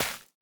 Minecraft Version Minecraft Version 1.21.5 Latest Release | Latest Snapshot 1.21.5 / assets / minecraft / sounds / block / hanging_roots / break1.ogg Compare With Compare With Latest Release | Latest Snapshot